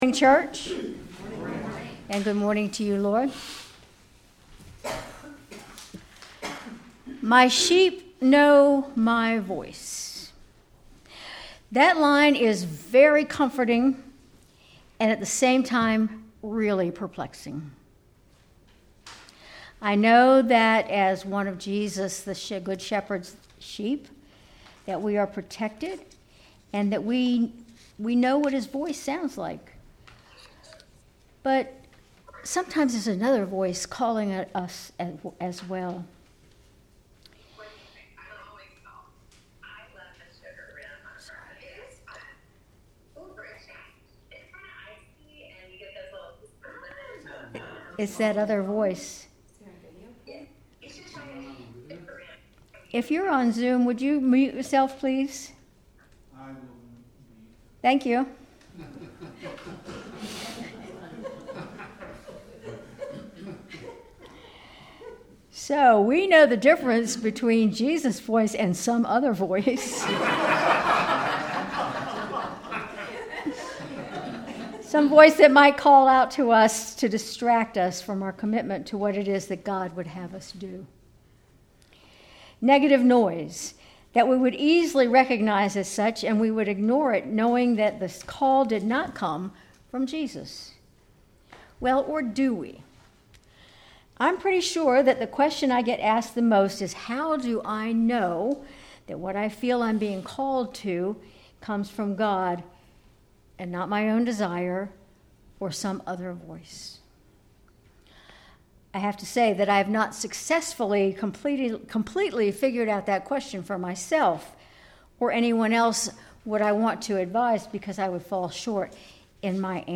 Sermon May 11, 2025